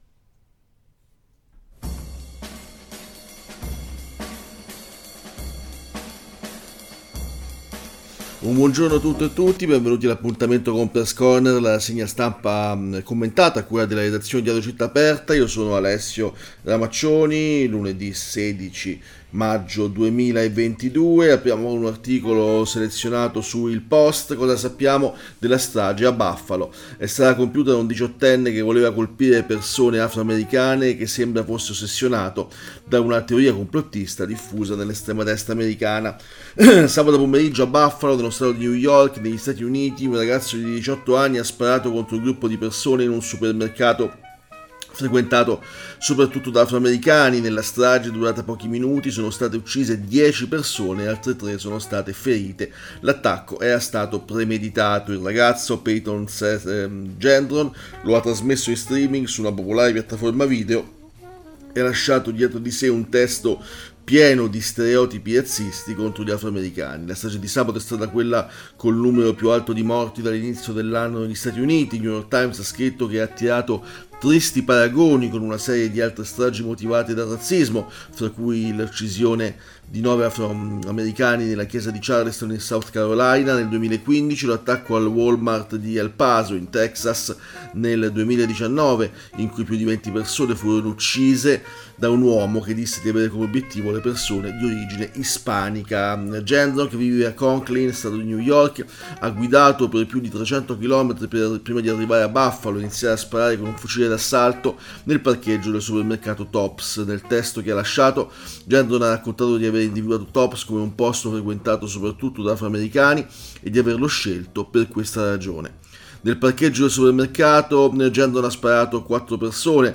Leggiamo e commentiamo insieme articoli di approfondimento apparsi su quotidiani, periodici, blog, siti specializzati su tutti i temi di interesse del momento.